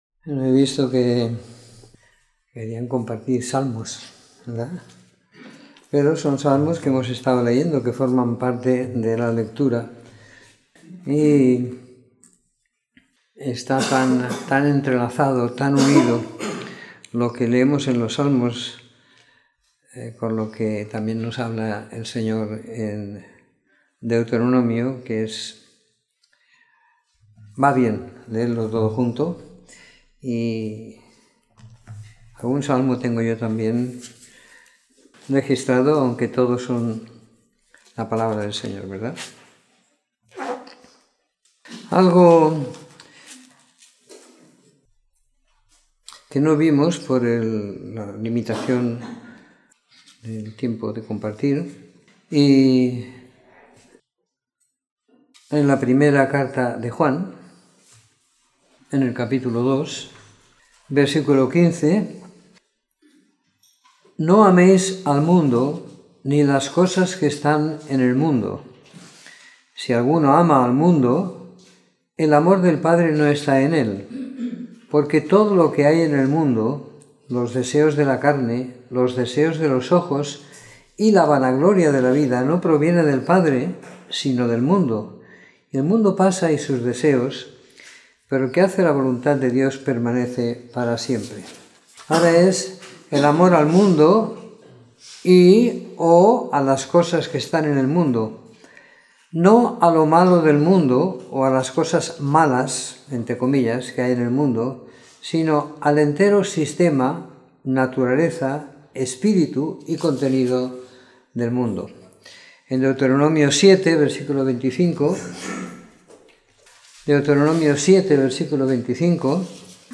Comentario en Deuteronomio 19-34